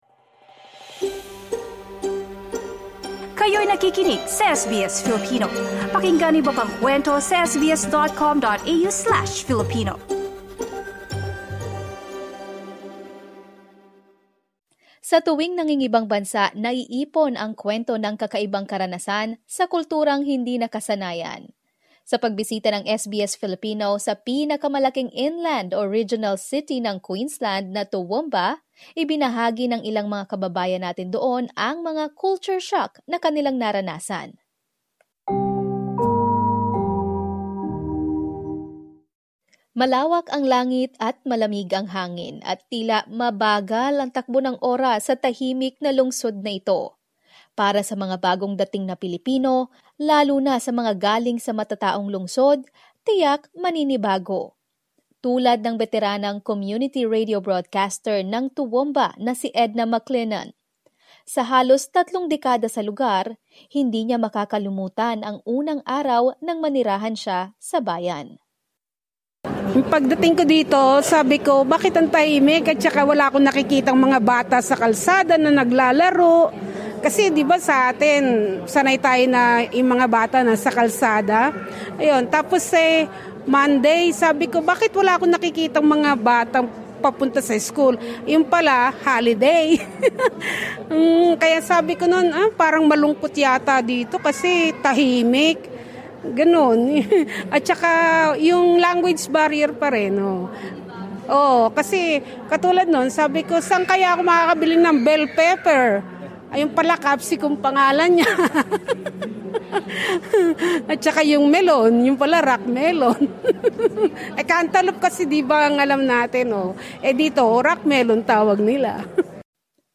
SBS visits Toowoomba for a special live broadcast, celebrating 50 years of giving voice to multicultural Australia — with heartwarming stories from the Filipino community.